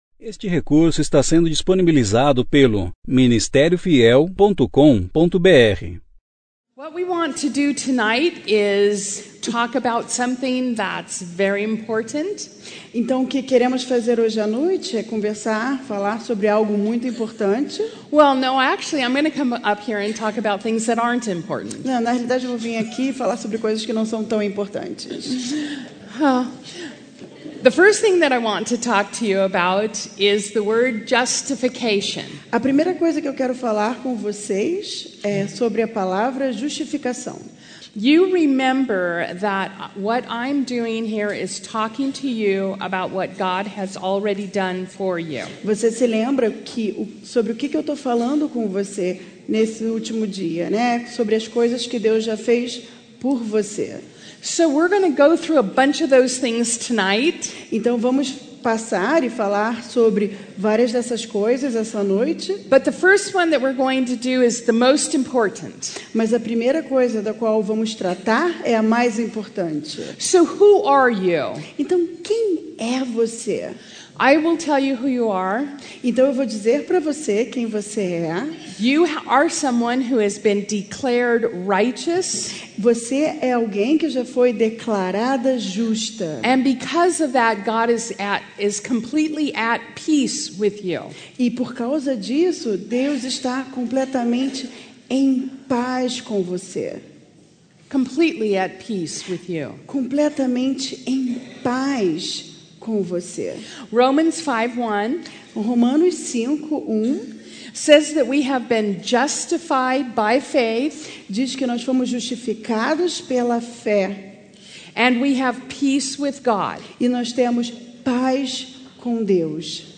Conferência: 1ª Conferência Fiel para Mulheres – Brasil Tema